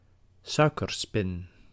Ääntäminen
Ääntäminen : IPA: [ˈsœʏkərˌspɪn] Tuntematon aksentti: IPA: /ˈsœːkərˌspɪn/ Haettu sana löytyi näillä lähdekielillä: hollanti Käännös Substantiivit 1. barbe à papa {f} Suku: f .